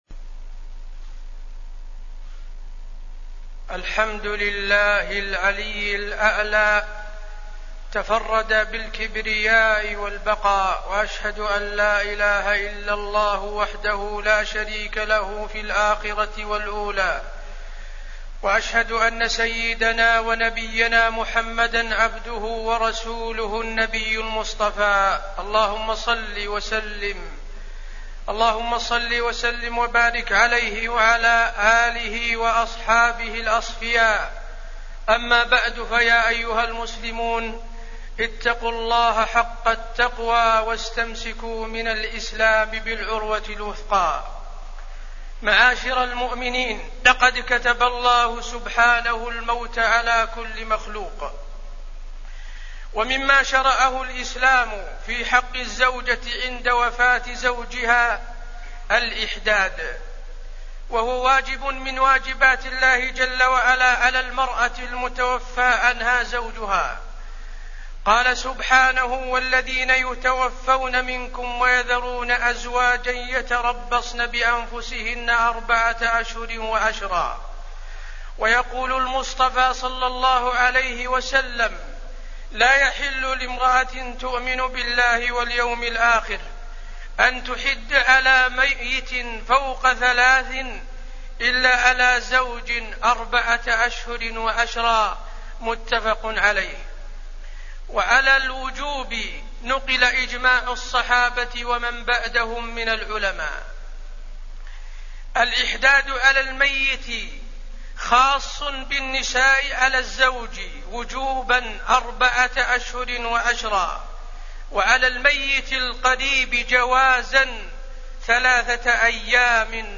تاريخ النشر ١٢ جمادى الآخرة ١٤٣٠ هـ المكان: المسجد النبوي الشيخ: فضيلة الشيخ د. حسين بن عبدالعزيز آل الشيخ فضيلة الشيخ د. حسين بن عبدالعزيز آل الشيخ الإحداد للمتوفى عنها زوجها